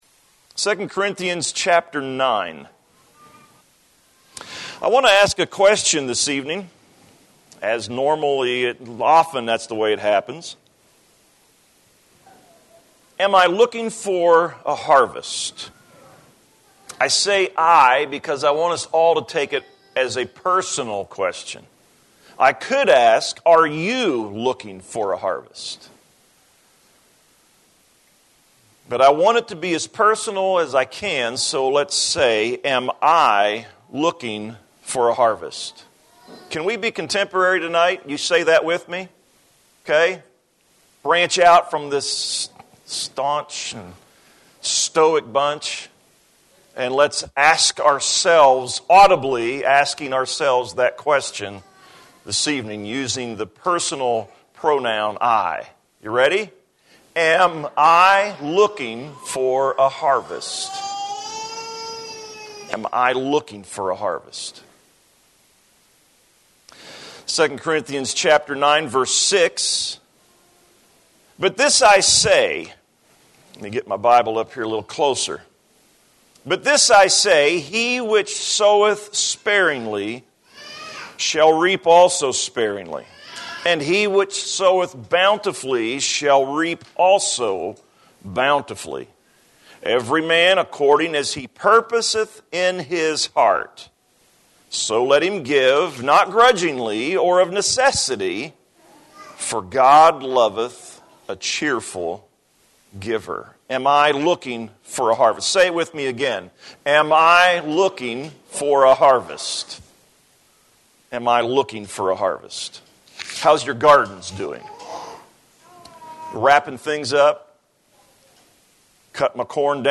Service